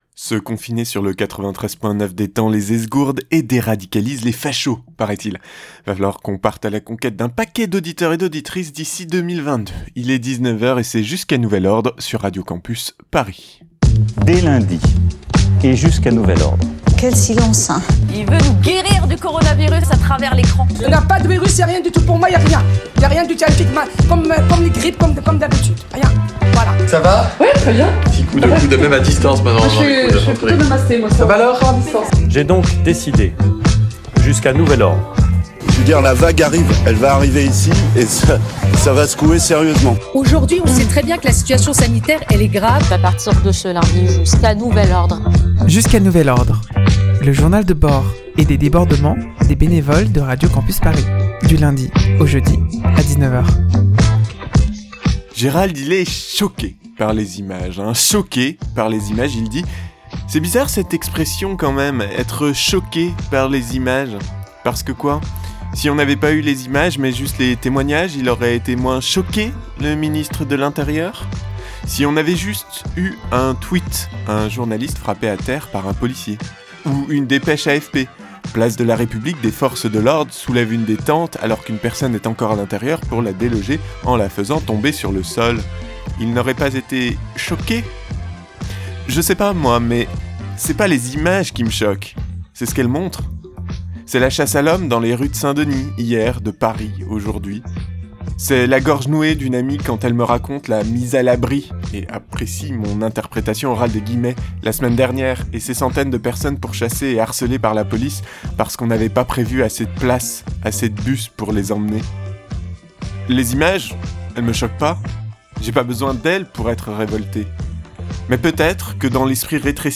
Emission du 25 novembre